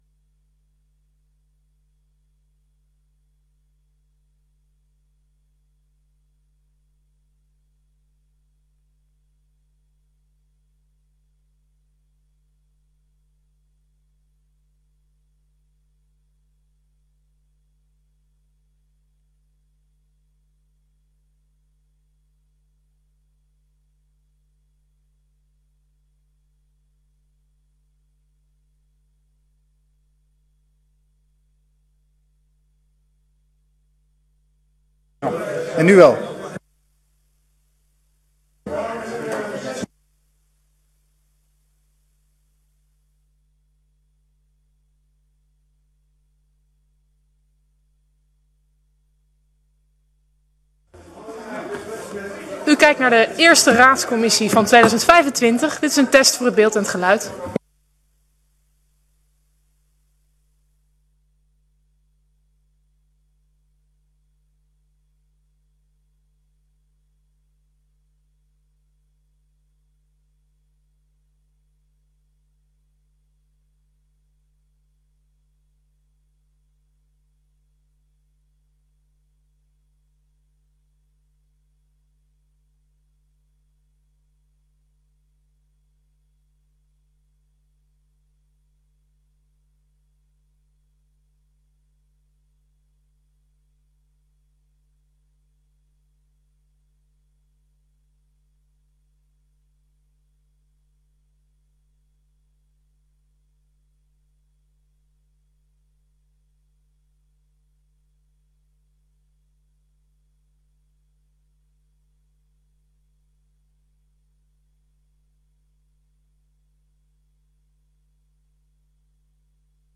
Raadscommissie 06 januari 2025 19:30:00, Gemeente Dalfsen
Download de volledige audio van deze vergadering